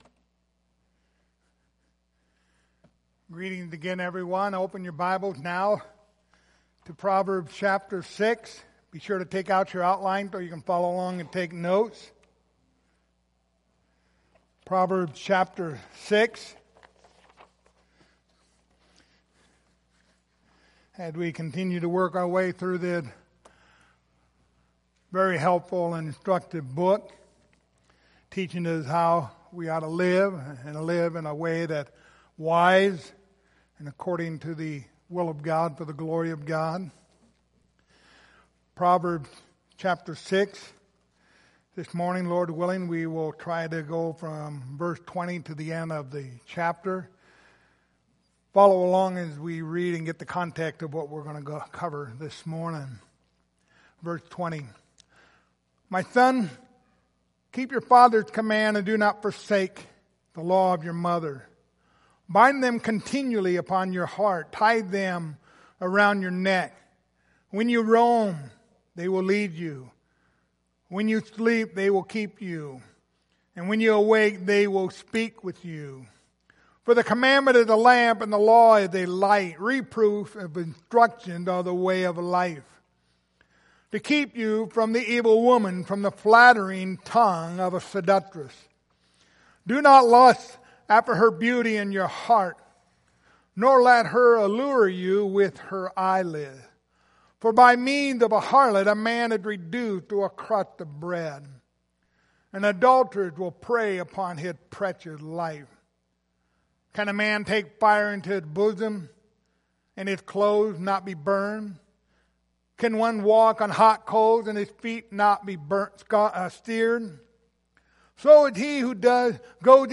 Passage: Proverbs 6:20-35 Service Type: Sunday Morning